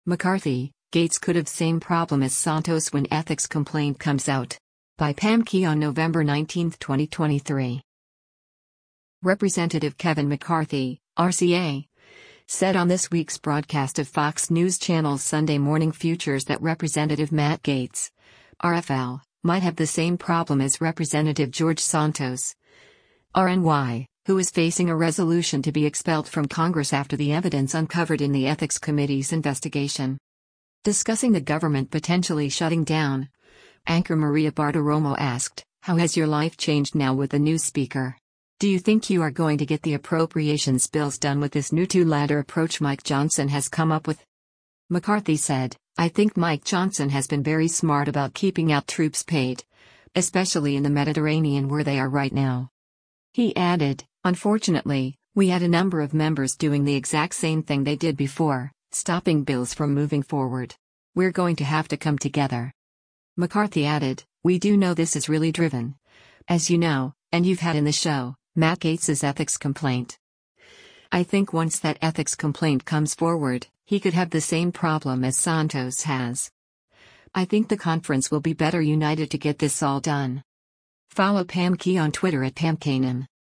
Representative Kevin McCarthy (R-CA) said on this week’s broadcast of Fox News Channel’s “Sunday Morning Futures” that Rep. Matt Gaetz (R-FL) might have the “same problem” as Rep. George Santos (R-NY), who is facing a resolution to be expelled from Congress after the evidence uncovered in the Ethics Committee’s investigation.
Discussing the government potentially shutting down, anchor Maria Bartiromo asked, “How has your life changed now with the new Speaker? Do you think you are going to get the appropriations bills done with this new two-ladder approach Mike Johnson has come up with?”